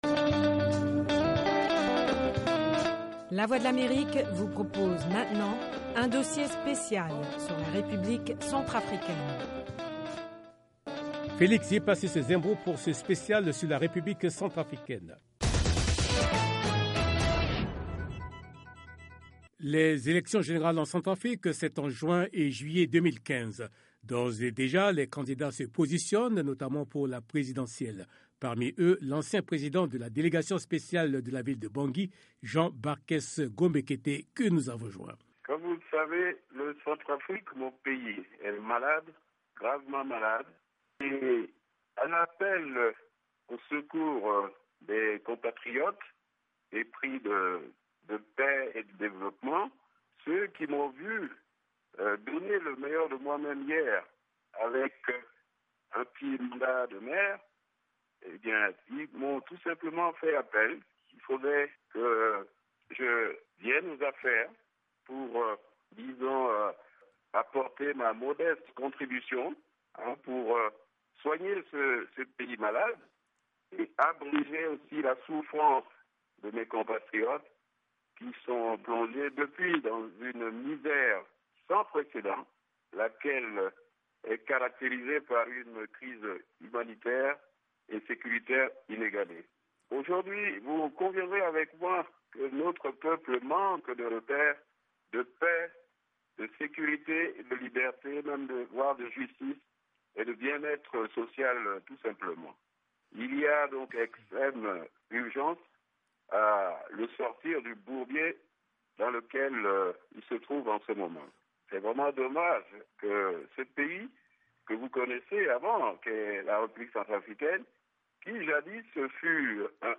RCA: Reportage Special